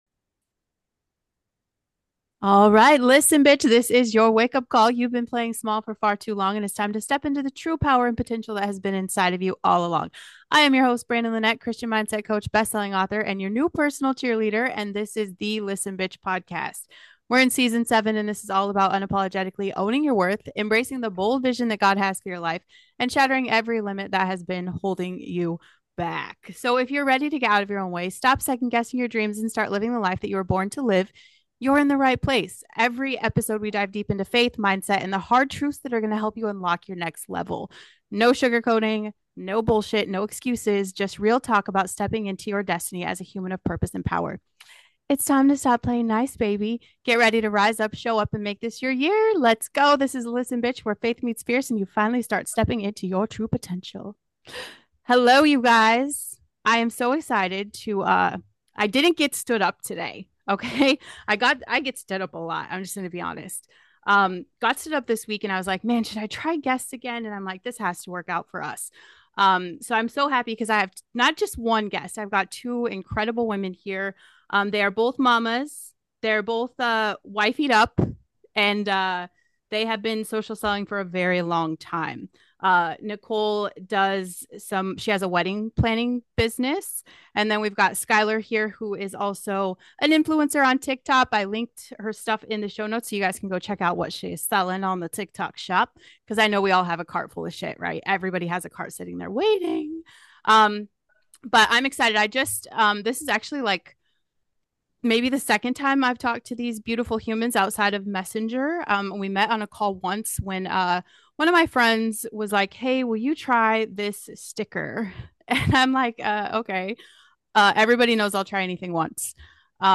Tune in as they discuss the incredible benefits of their patches, from overcoming bad habits and experiencing better sleep to fostering peace, joy, and mental clarity.